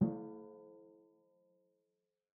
Timpani8D_hit_v3_rr2_main.mp3